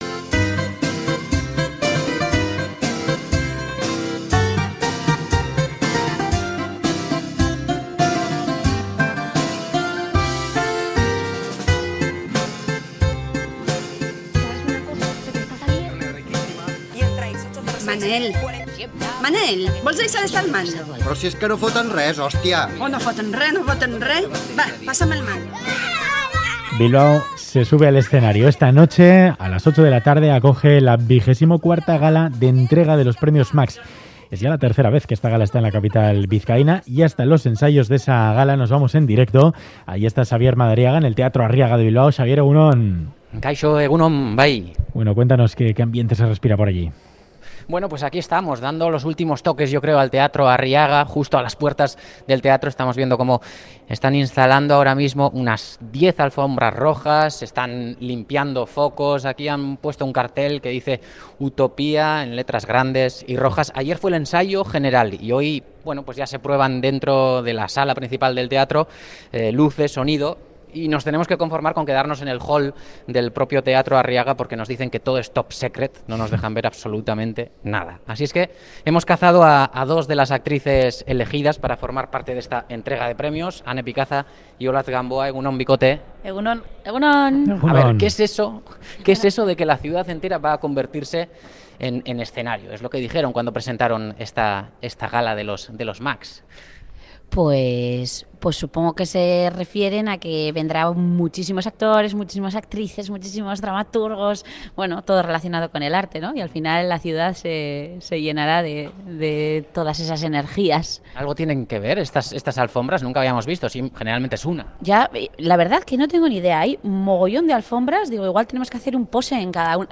Radio Euskadi asiste al ensayo de la gala de los Premios Max
Esta noche se celebra en el Teatro Arriaga de Bilbao la gala de los Premios Max a las 20:00 horas. Radio Euskadi asiste al último ensayo.